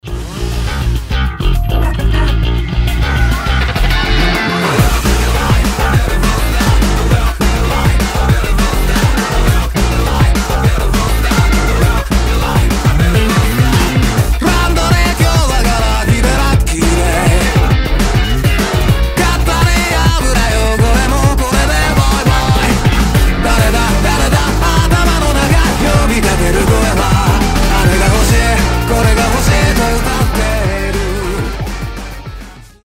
• Качество: 320, Stereo
громкие
энергичные
бодрые
j-rock